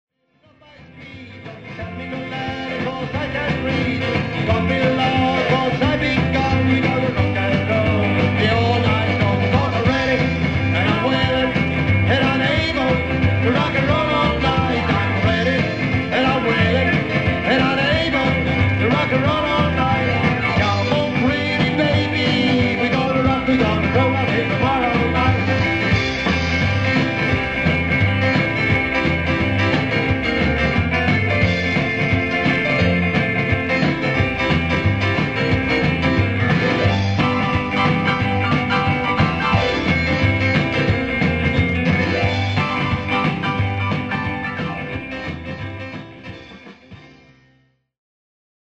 Rock med Spinning Wheels Gruppen Spinning Wheels rockade loss och spelade till dansen i bygdegården lördag den 11 november.
Kvartetten av före detta dansbandsmusiker bevisade att än sitter takterna i och alla är de virtuoser på sina instrument.
rock.mp3